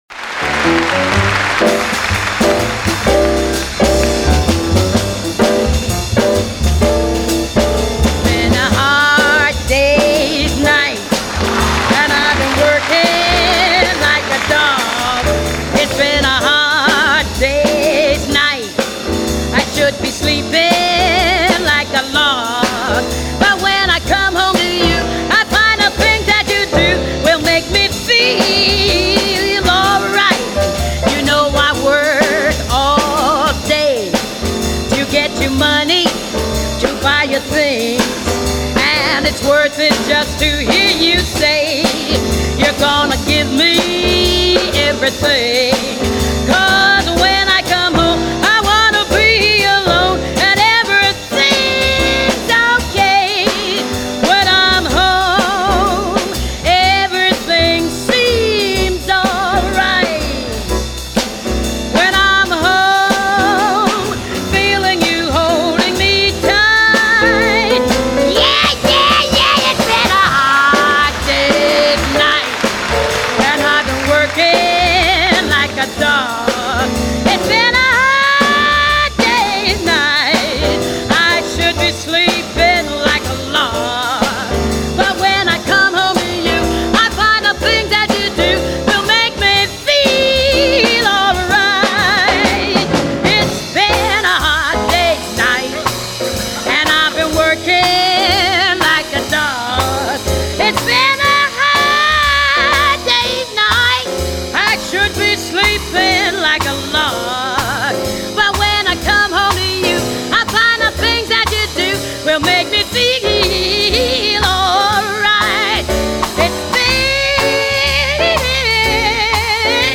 Canto
Jazz